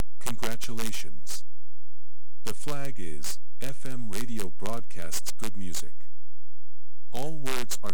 이후 WBFM Receive 블럭을 이용하여 FM 신호를 받아들이고, 이를 복조하여 실수형 오디오 신호로 변환합니다.